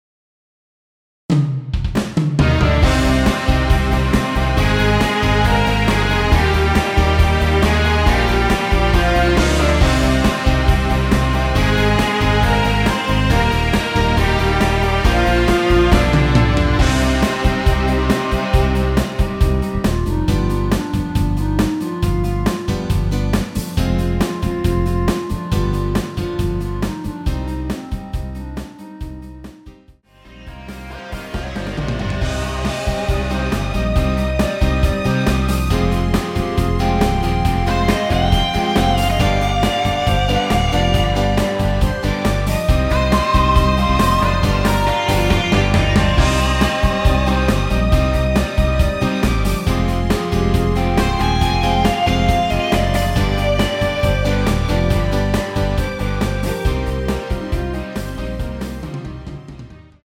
원키에서(-2)내린 멜로디 포함된 MR입니다.
Cm
앞부분30초, 뒷부분30초씩 편집해서 올려 드리고 있습니다.
중간에 음이 끈어지고 다시 나오는 이유는